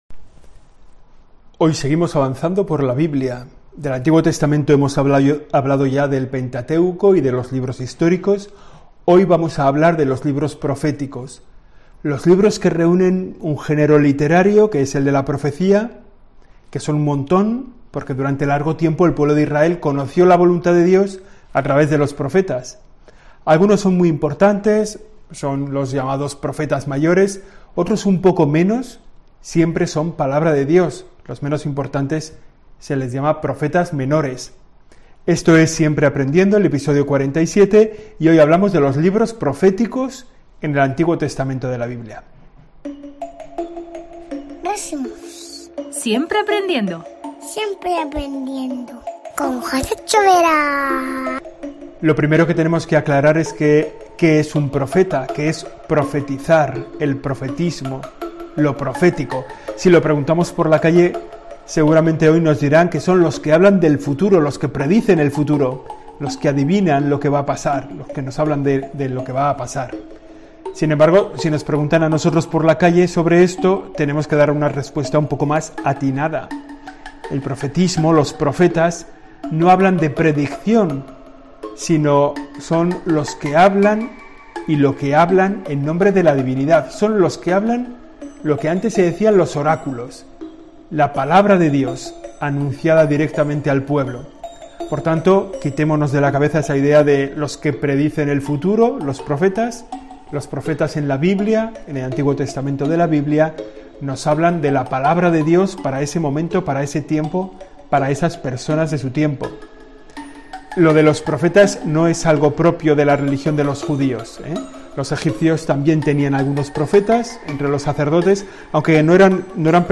El periodista y sacerdote